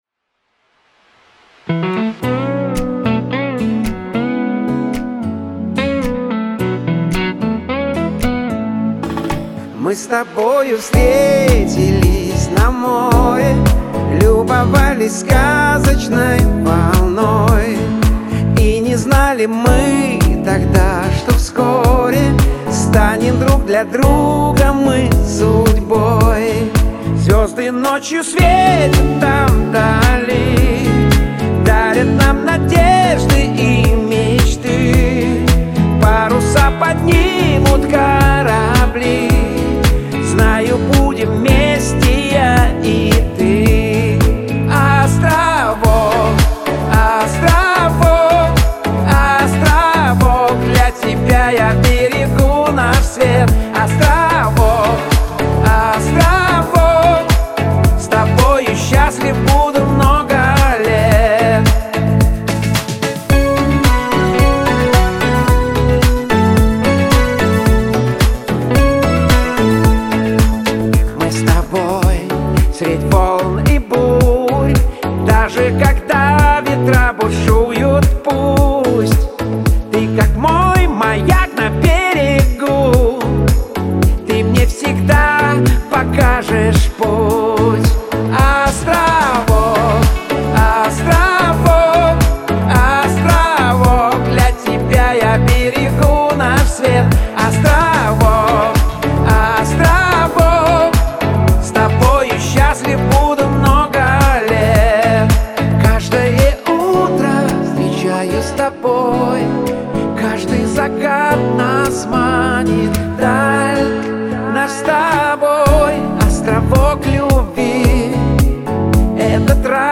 Лирика , эстрада , диско , pop